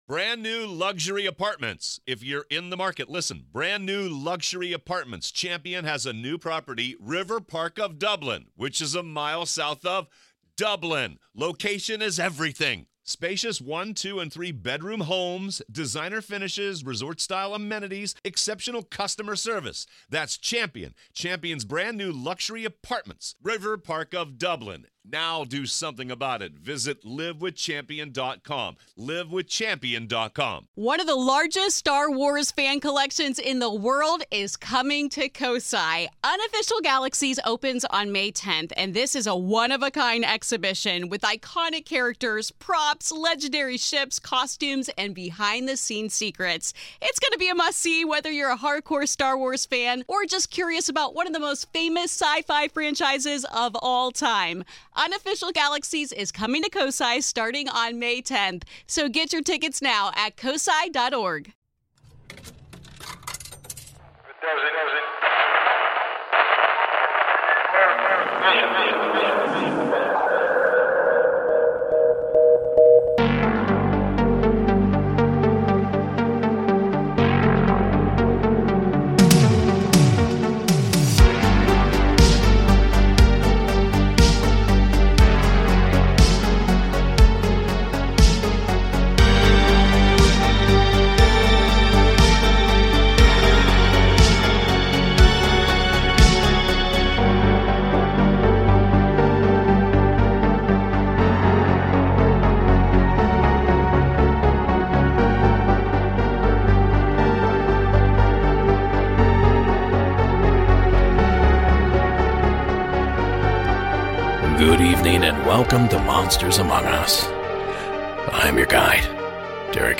Season 13 Episode 3 of Monsters Among Us Podcast, true paranormal stories of ghosts, cryptids, UFOs and more told by the witnesses themselves.